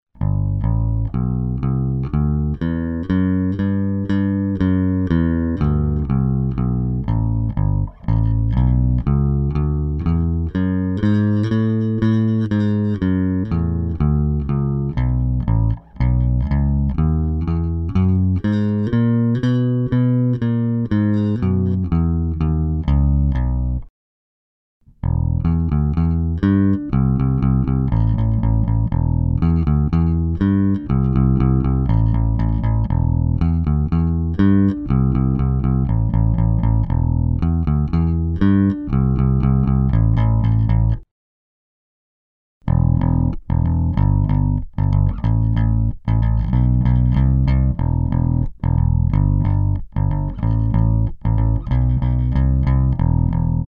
Proč při stejném dohmatu, když hraju trsátkem Warwick drnčí o pražce a Squier je v pohodě.
Tak jsem schválně nahrál ukázku, - jestli poznáte, co je warw a co squier .
A přijde mi to mrtvé, na to že je tam mám kousek přes měsíc a vůbec jsem na to nehrál...